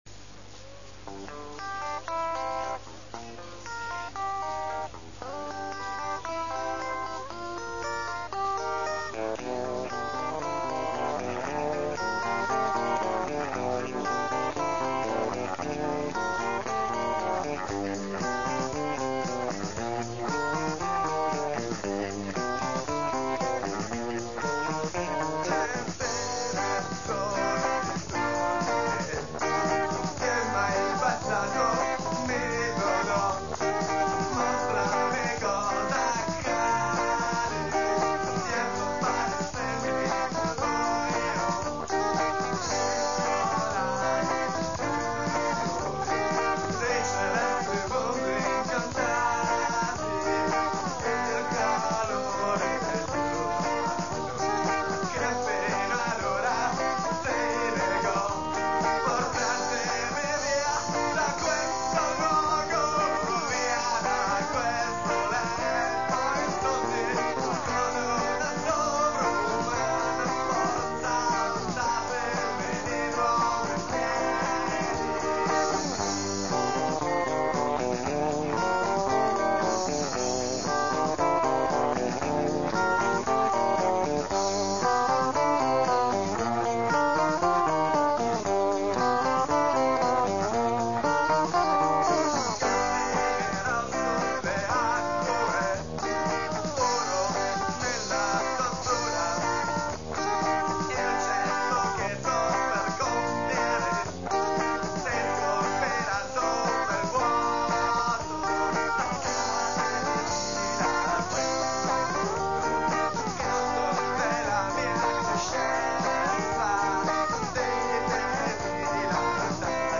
voce, chitarra
chitarra, tastiere
basso
batteria elettronica
Registrato al Push-Pull studio di Empoli nel 1984